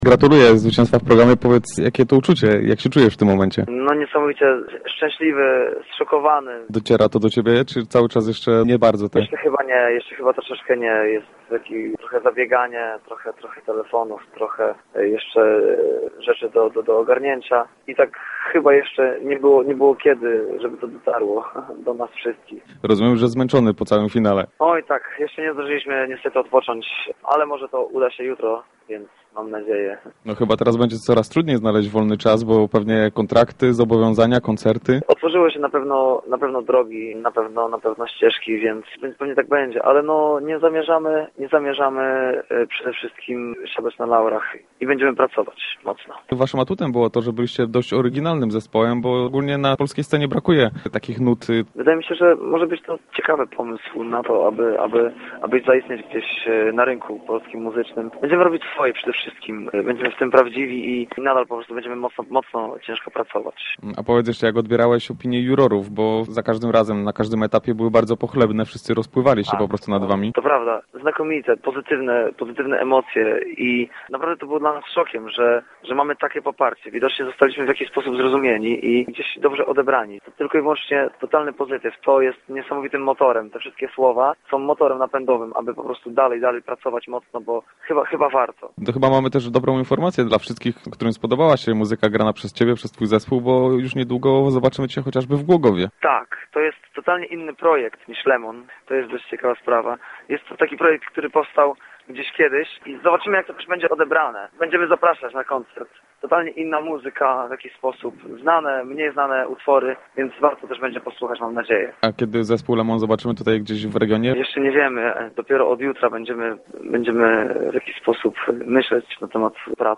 Cała rozmowa z Igorem Herbutem, liderem zespołu Lemon, specjalnie dla Radia Elka.